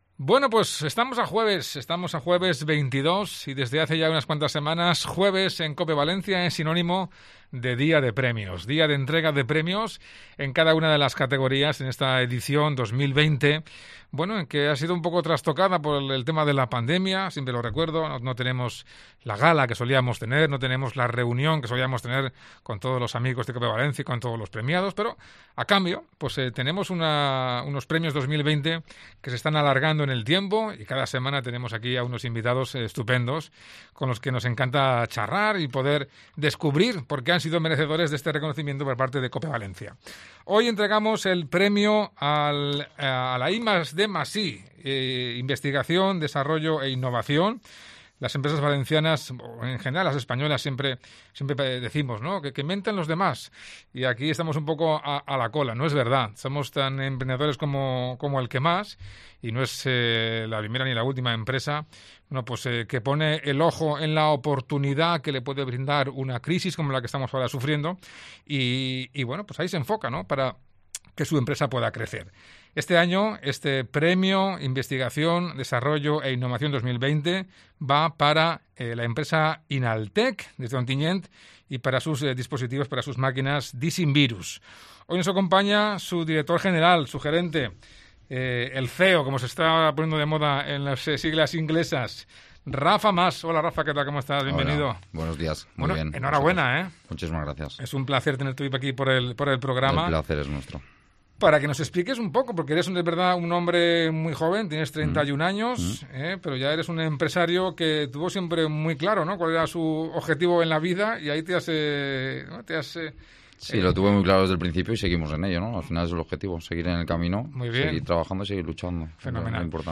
Entrevista Premio Innovación, Desarrollo e Investigación 2020